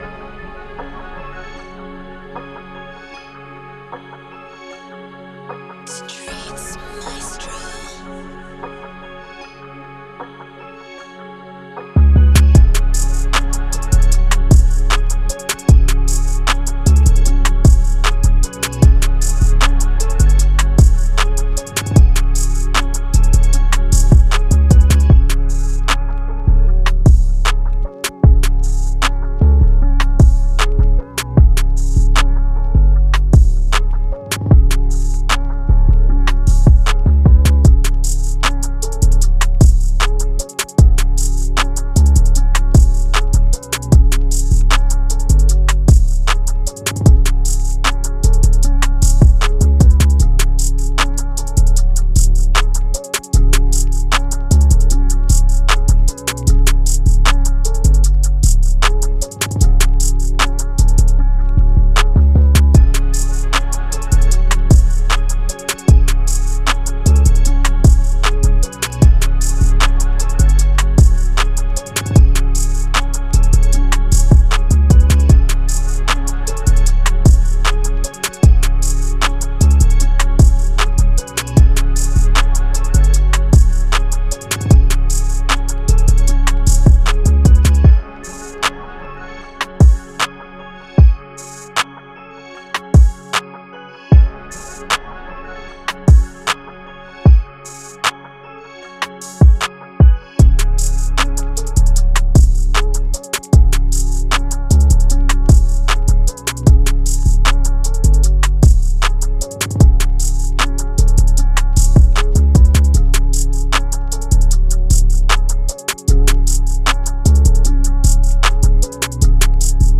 Hard Type Beat
Moods: hard, dark, dramatic
Genre: Rap
Tempo: 153
a hard, dark, dramatic, type beat.